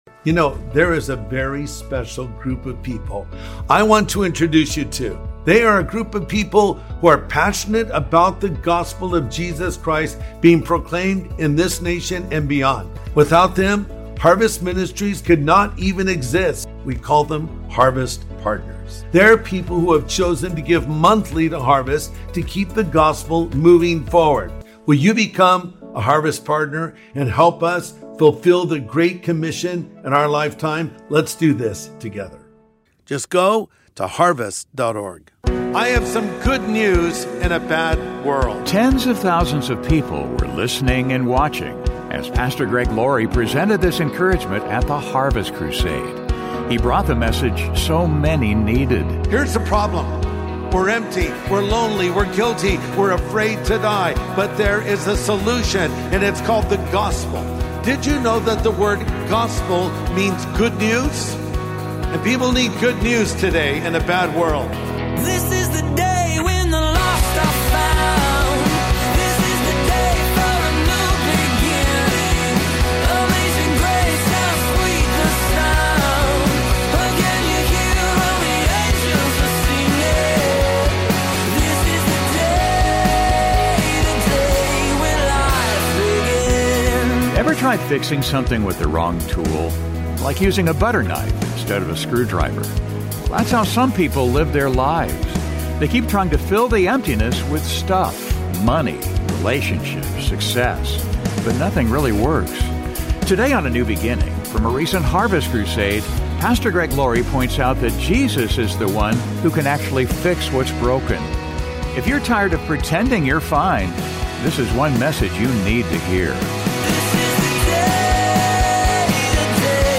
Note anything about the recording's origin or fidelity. from a recent Harvest Crusade